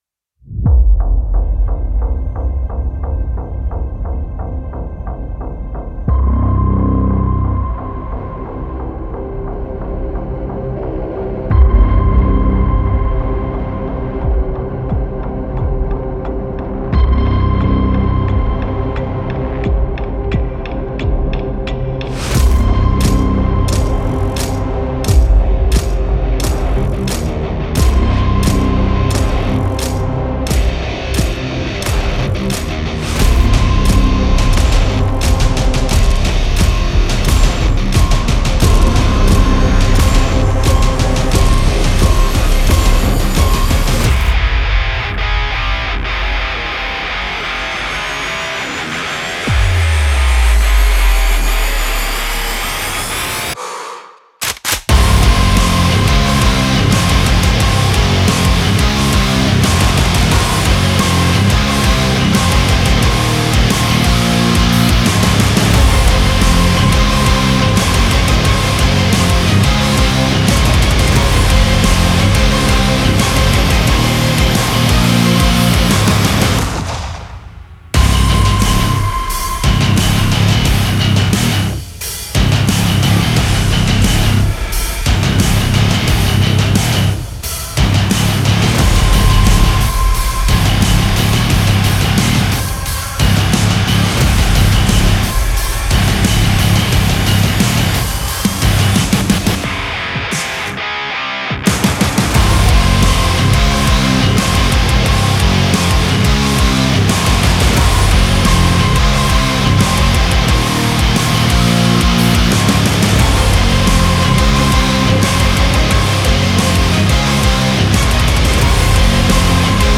Жанр: Metal